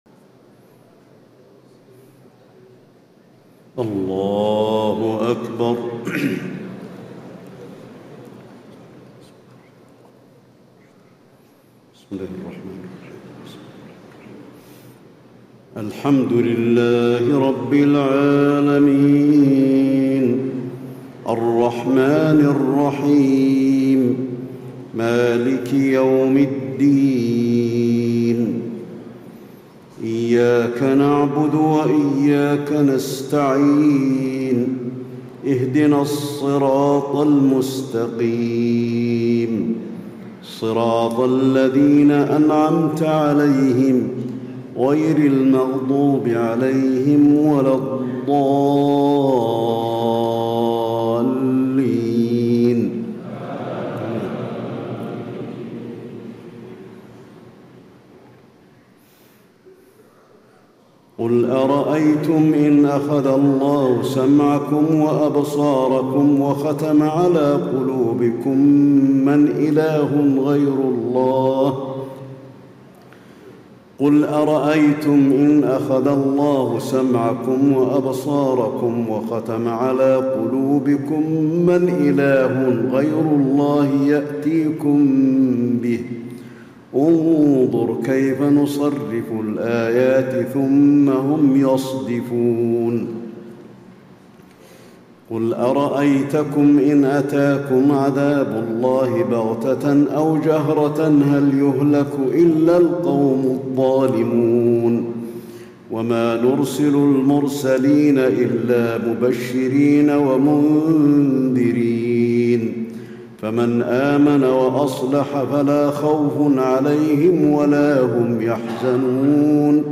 تهجد ليلة 27 رمضان 1436هـ من سورة الأنعام (46-110) Tahajjud 27 st night Ramadan 1436H from Surah Al-An’aam > تراويح الحرم النبوي عام 1436 🕌 > التراويح - تلاوات الحرمين